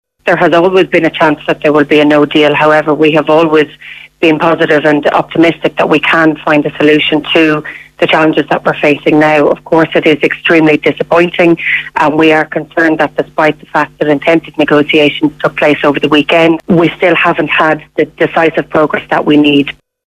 The European Affairs Minister says the government is concerned there still hasn’t been decisive progress on Brexit.